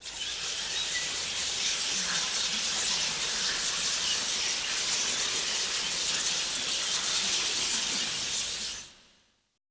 Index of /90_sSampleCDs/Best Service - Extended Classical Choir/Partition I/VOICE ATMOS
WHISPERING-R.wav